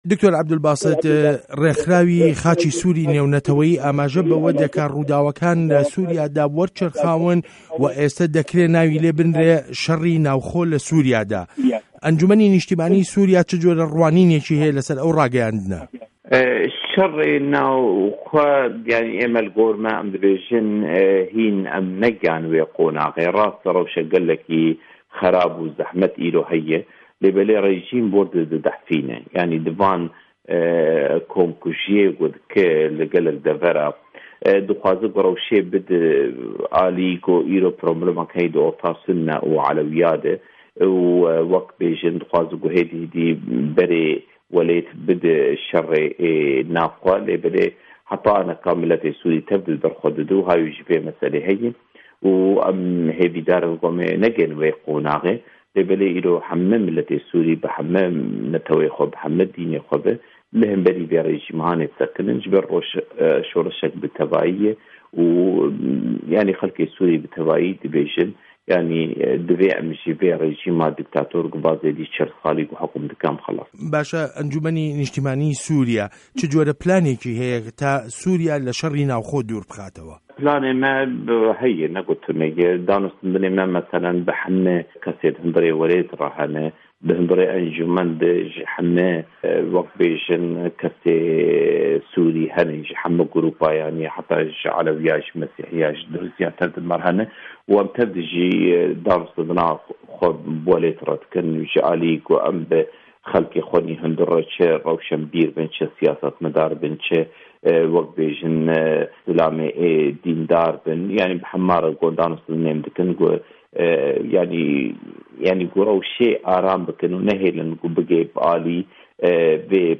وتووێژ له‌گه‌ڵ دکتۆر عه‌بدولباست سه‌یدا